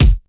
Kick_97.wav